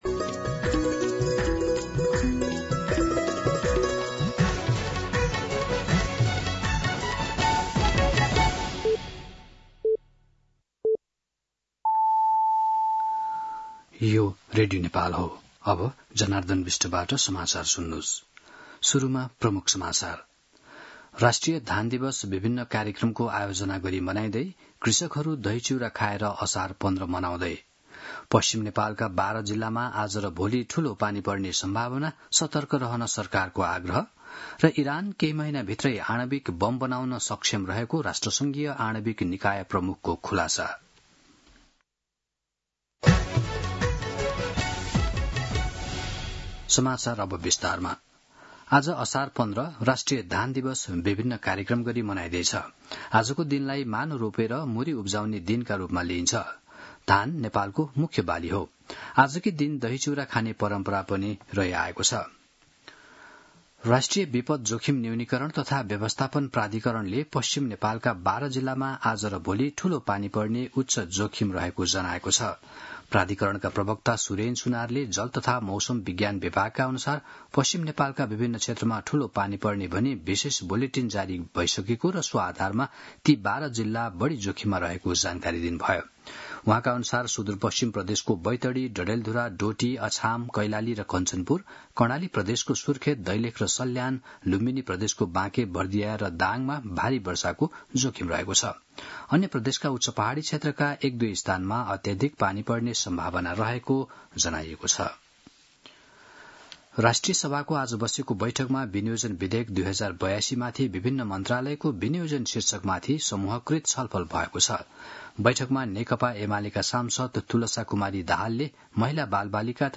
An online outlet of Nepal's national radio broadcaster
दिउँसो ३ बजेको नेपाली समाचार : १५ असार , २०८२